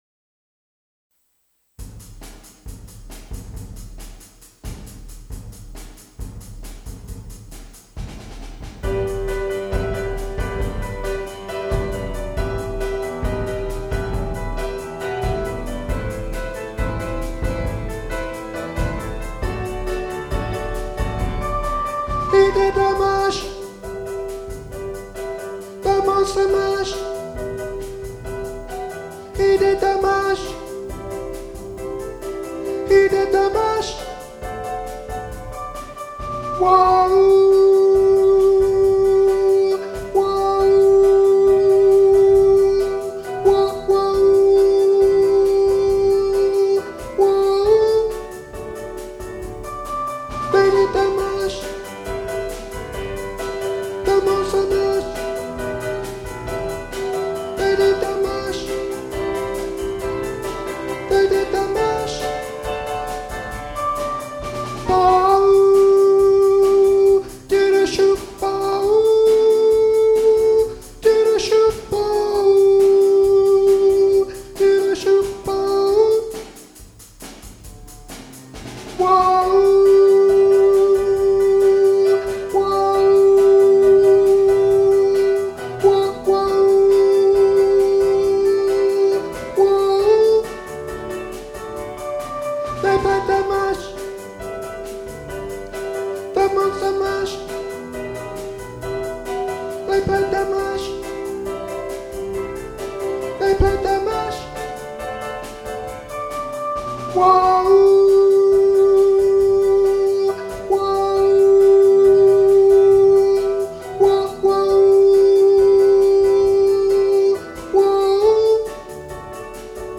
Monster Mash Soprano 1 | Ipswich Hospital Community Choir
Monster-Mash-Soprano-1.mp3